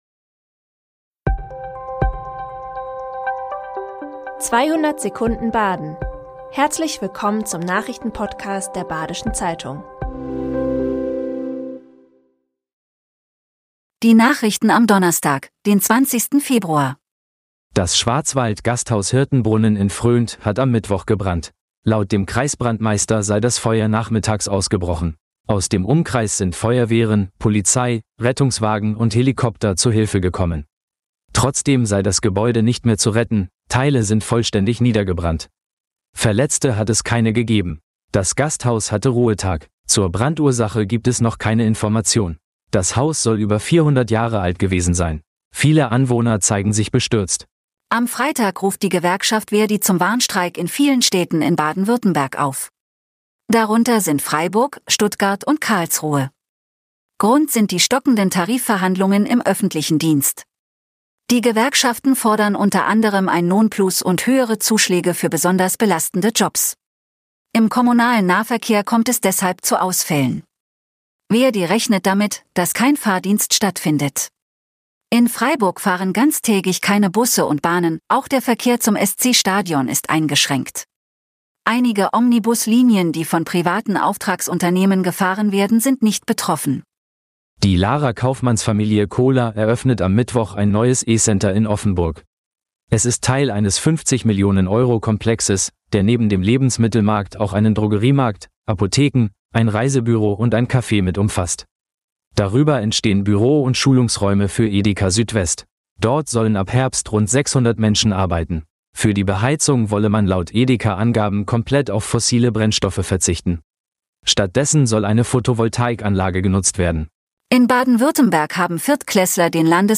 “200 Sekunden Baden” ist der tägliche Nachrichten-Podcast der Badischen Zeitung. Montags bis freitags erscheint um 6.30 Uhr unser News-Format mit fünf wichtigen Nachrichten.
Die Nachrichten werden Ihnen in 200 Sekunden von einer künstlichen Computerstimme vorgelesen.